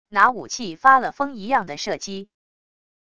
拿武器发了疯一样地射击wav音频